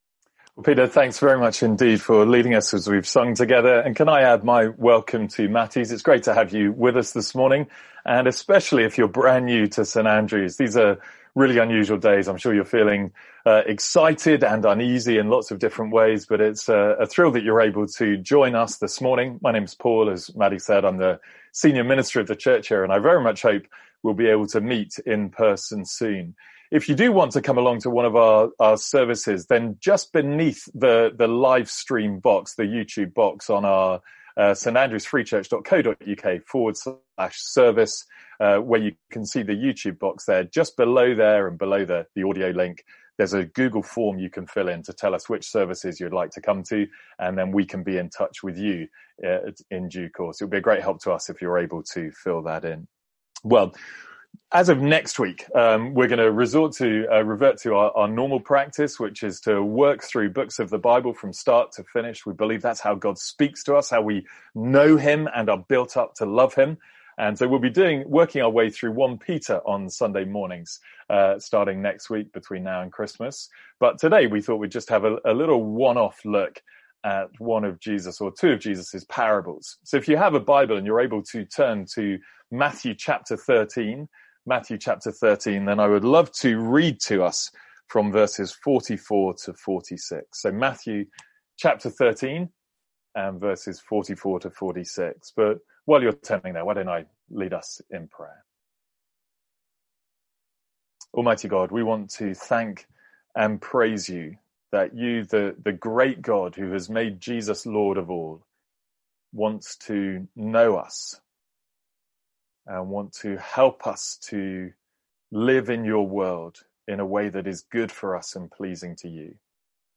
Sermons | St Andrews Free Church
From our morning service.